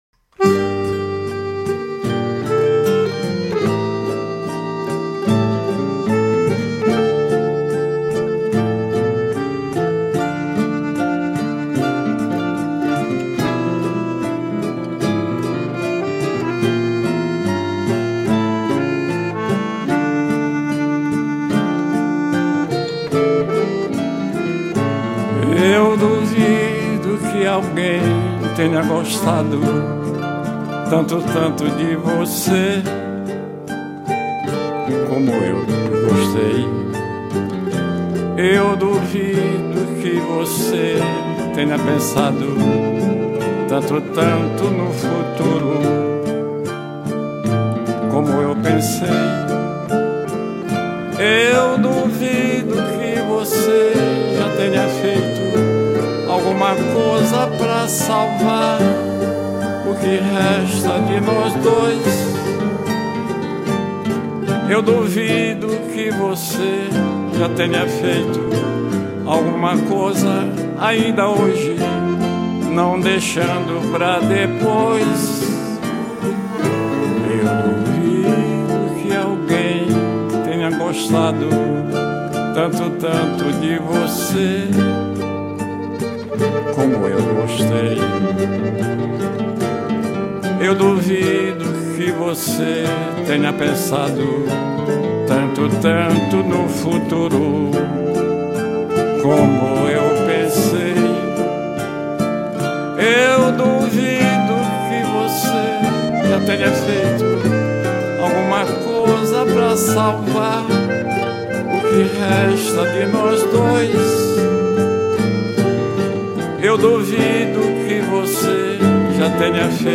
1402   07:47:00   Faixa:     Samba Canção